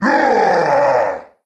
One of Bowser's voice clips in Mario & Luigi: Brothership
BrothershipBowserRoar2.oga.mp3